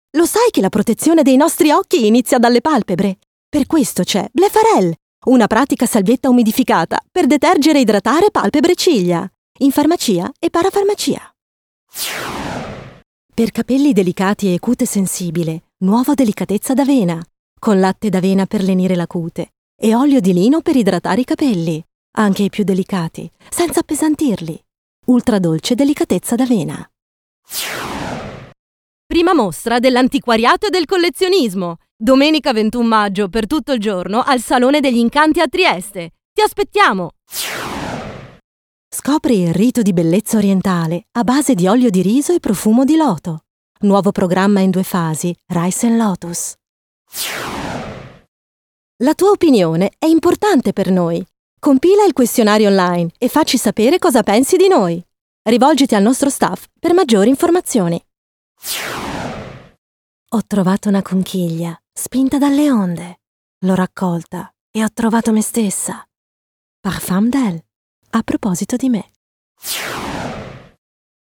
Professional singer and Italian voice talent whose expressive and versatile voice can range from smooth and sexy to friendly and upbeat, from warm and compassionate to authoritative and professional and is ideal for radio and TV commercials, telephone on hold, e-learning and corporates.
Sprechprobe: Werbung (Muttersprache):